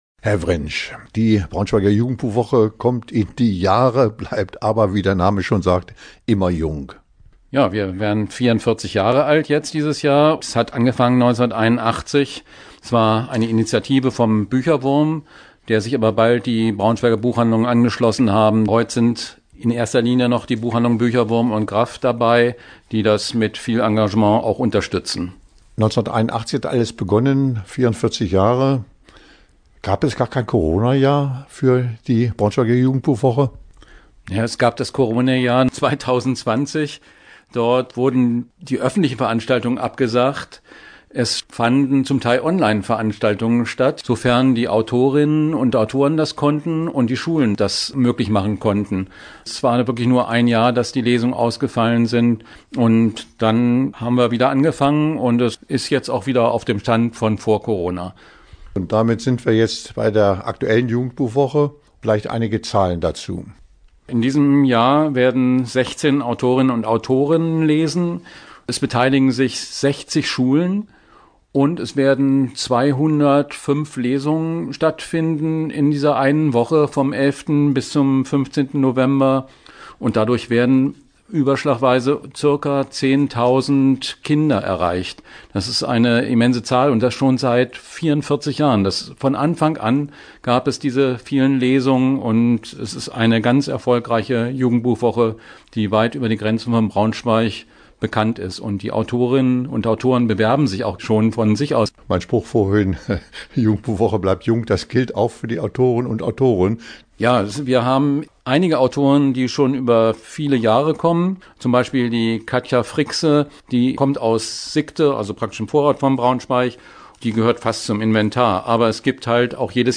Interview-Jugendbuchwoche.mp3